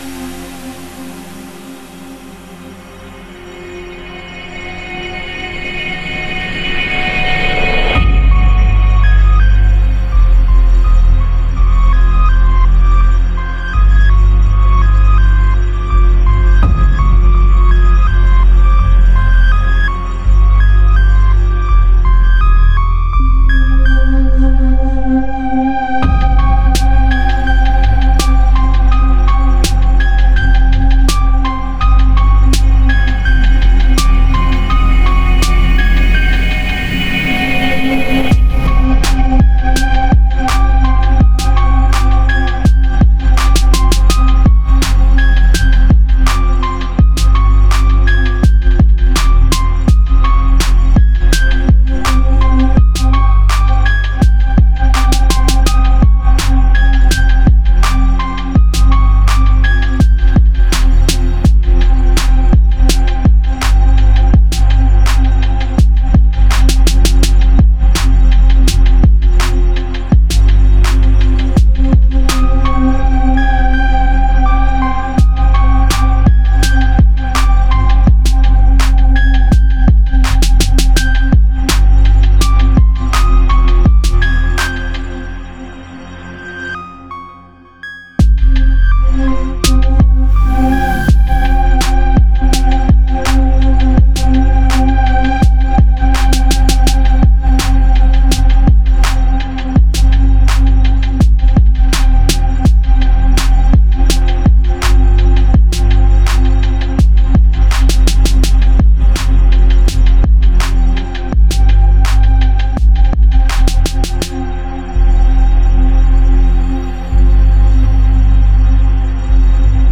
Hip-hop Умеренный 83 BPM